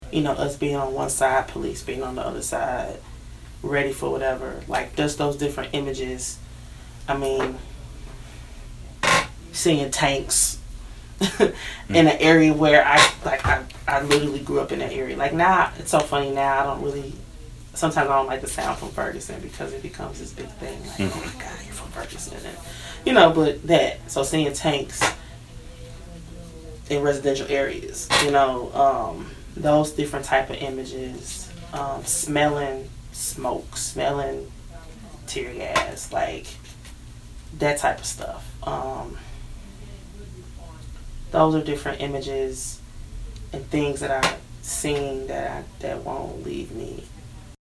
To get a sense of the richness of the interviews, please take a moment to listen to these sample clips.